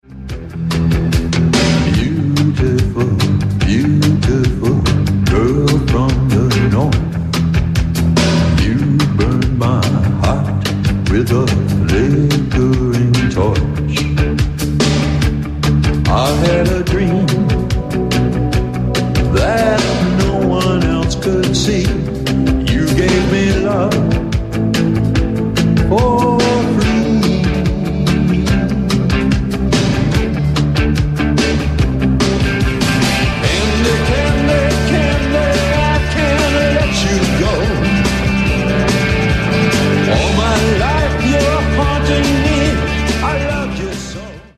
One of the more popsy-duets of the early 90s.